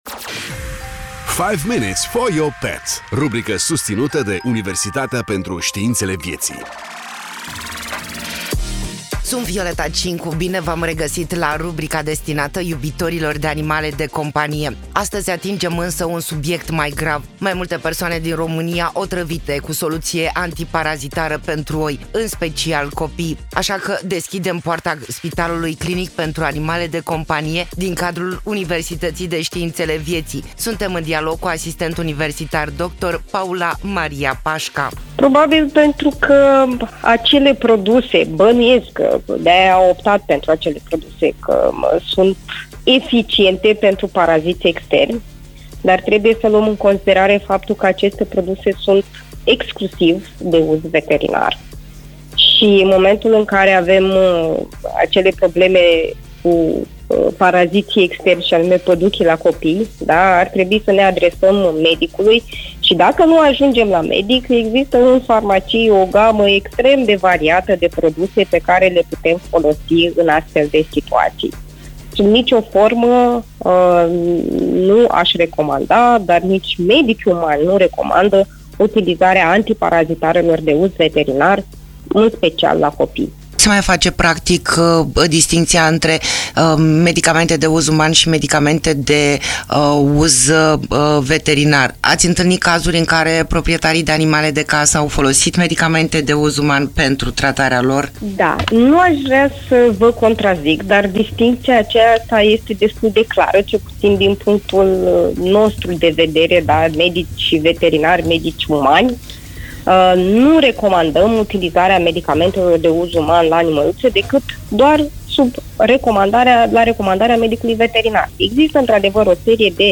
Bine v-am regăsit la rubrica destinată iubitorilor de animale de companie.